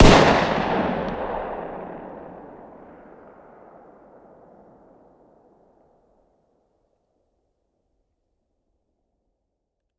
fire-dist-10x25-pist..>2024-09-10 22:10 504K